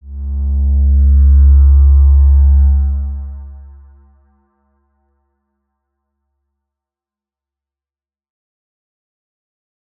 X_Windwistle-C#1-mf.wav